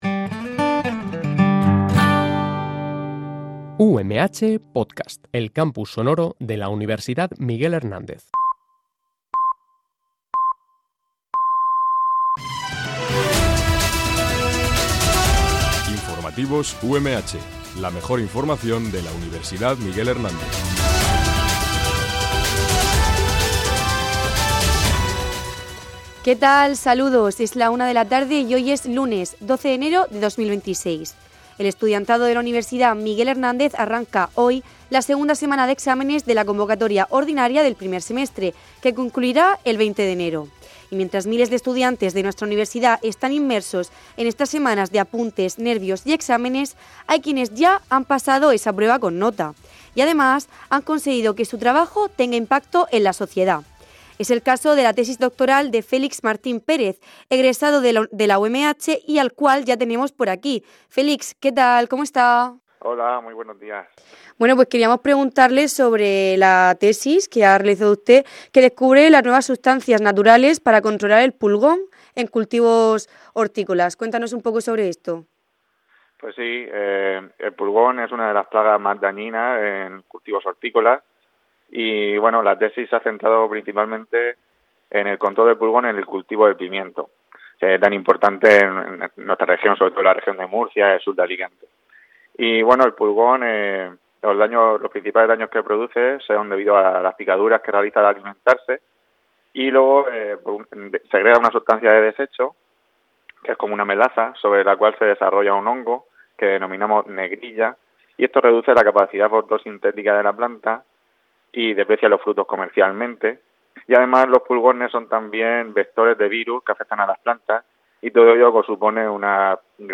Este programa de noticias se emite en directo, de lunes a viernes, en horario de 13.00 a 13.10 h.